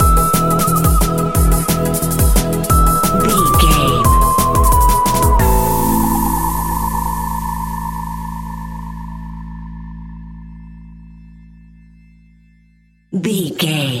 Aeolian/Minor
Fast
groovy
uplifting
futuristic
driving
energetic
drum machine
synthesiser
organ
electronic
sub bass
synth leads
synth bass